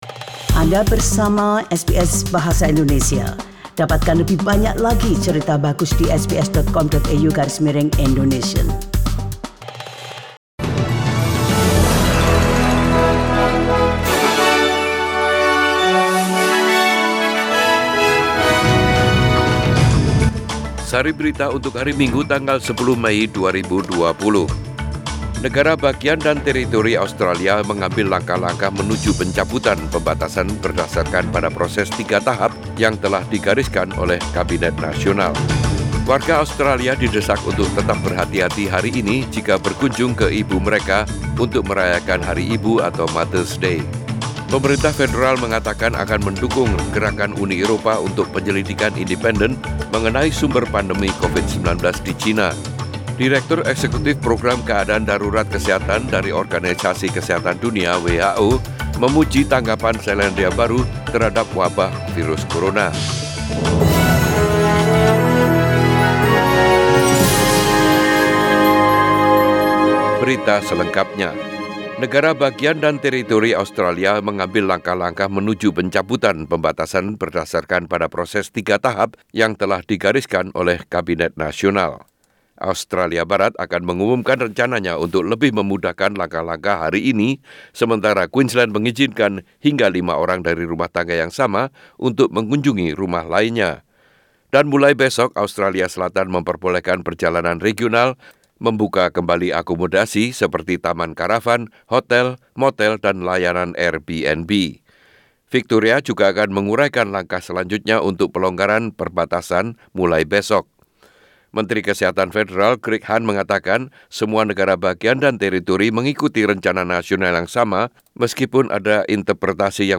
Warta Berita Radio SBS dalam Bahasa Indonesia - 10 Mei 2020